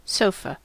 Ääntäminen
Synonyymit couch divan settee canape Ääntäminen : IPA : /ˈsoʊ.fə/ US : IPA : [ˈsoʊ.fə] Tuntematon aksentti: IPA : /ˈsəʊ.fə/ Haettu sana löytyi näillä lähdekielillä: englanti Käännös Ääninäyte Substantiivit 1.